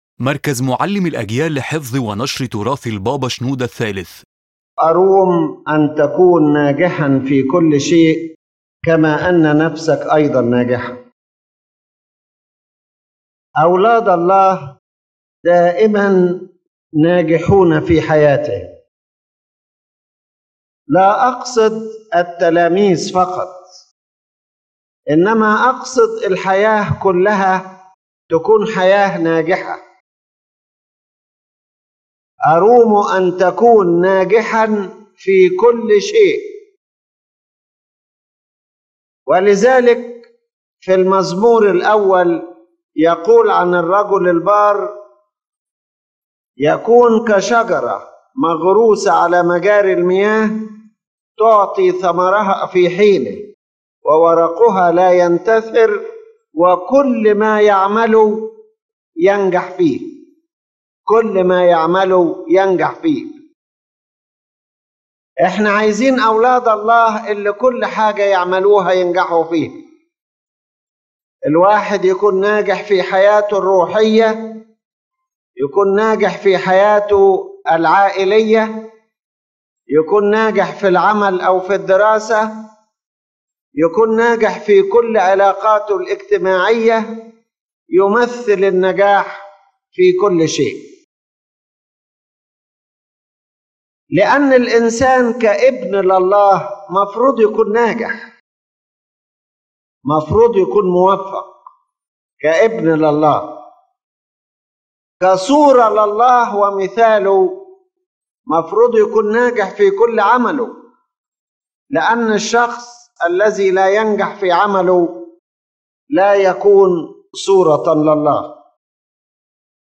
The lecture revolves around the concept of true success from a Coptic Orthodox spiritual perspective, where success is not measured only by external appearances or temporal achievements, but by inner success in one’s relationship with God and steadfastness in the spiritual path.